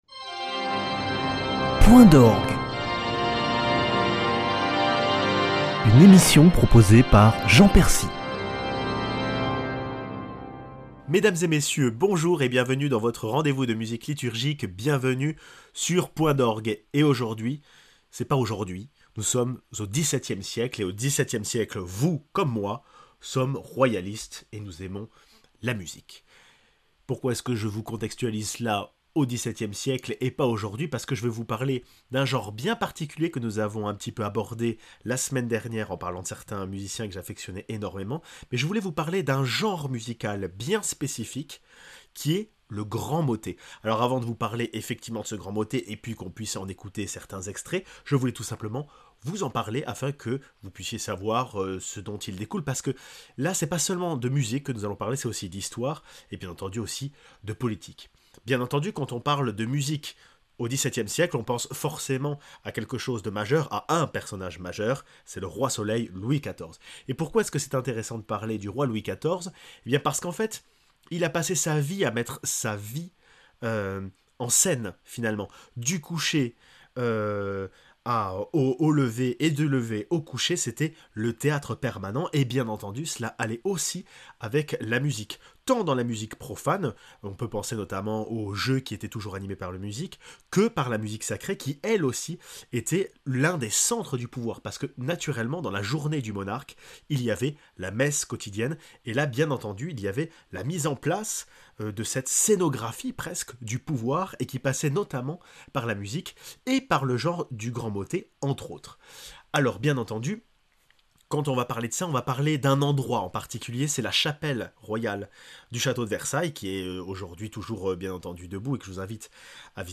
Genre particulier de la musique liturgique française, le grand motet fait partie du genre le plus pur de la magie musicale du grand siècle